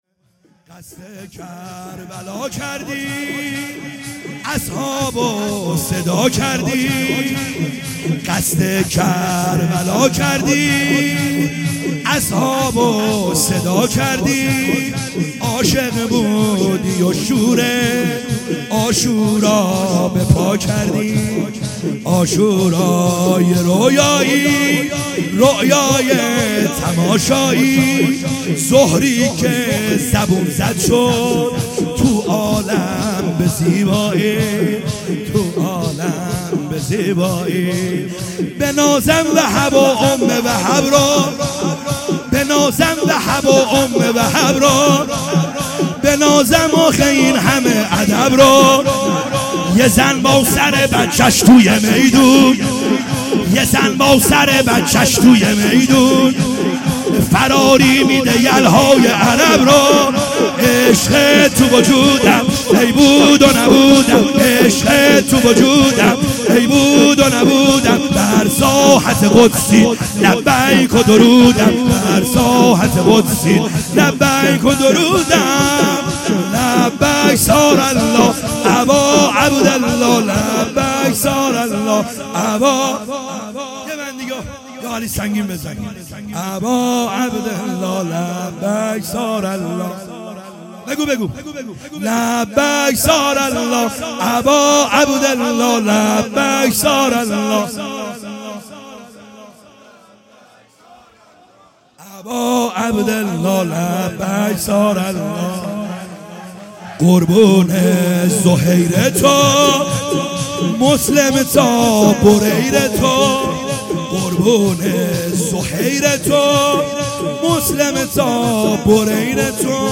خیمه گاه - بیرق معظم محبین حضرت صاحب الزمان(عج) - شور | قصد کربلا کردی